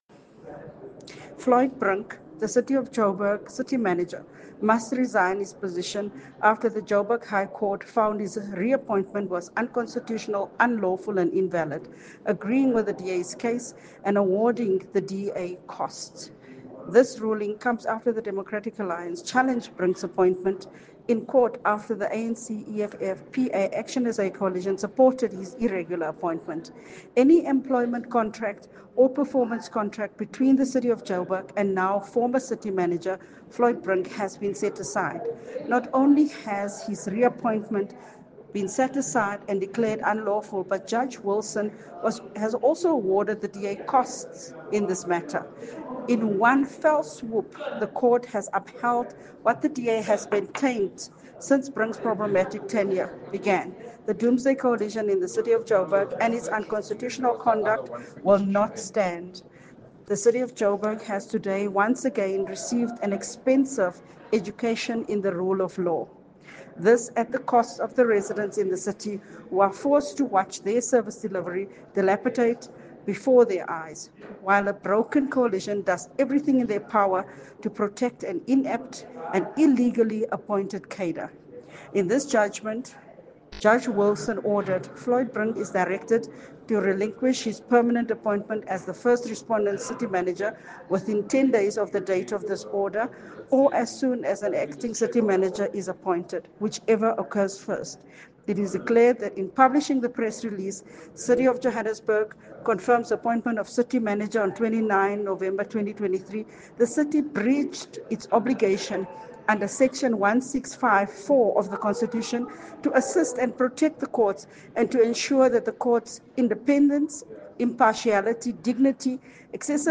Note to Editors: Please find a soundbite by Cllr Belinda Kayser-Echeozonjoku